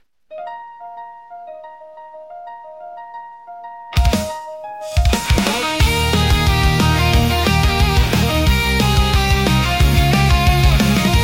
I like how the model can do Engrish too similar to Udio and other models.
This is a raw text2song gen, but it will be very interesting when combined with the cover feature for dubbing anime songs.